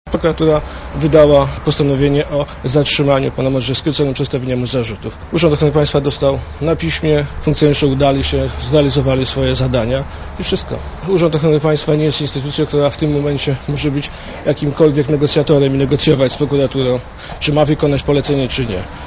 O zatrzymaniu Modrzejewskiego decydował prokurator prowadzący te sprawę - powiedział w czwartek Siemiątkowski dziennikarzom przed przesłuchaniem w katowickiej prokuraturze, badającej okoliczności zatrzymania Modrzejewskiego.